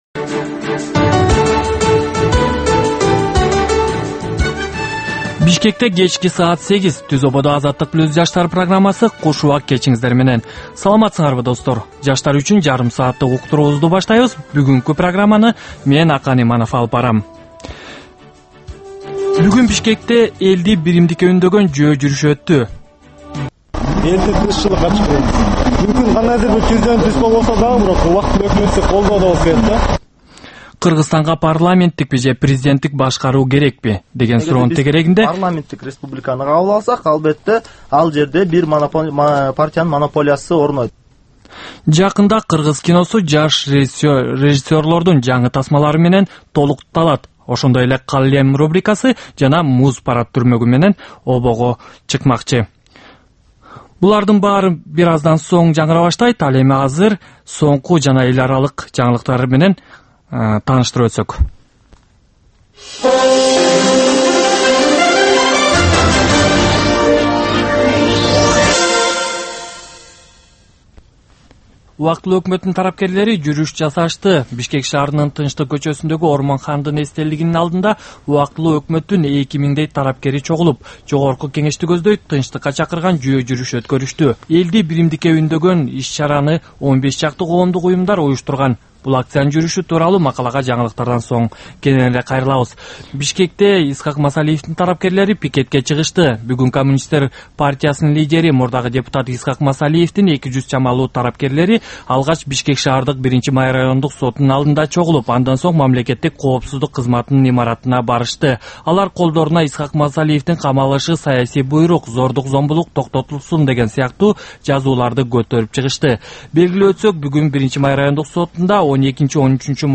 Бул жаштарга арналган кечки үналгы берүү жергиликтүү жана эл аралык кабарлардын чакан топтому, ар кыл репортаж, сереп, маек, маданий, спорттук, социалдык баян, тегерек үстөл четиндеги баарлашуу жана башка кыргызстандык жаштардын көйгөйү чагылдырылган берүүлөрдөн турат. "Азаттык үналгысынын" бул жаштар берүүсү Бишкек убакыты боюнча саат 20:00дан 20:30га чейин обого түз чыгат.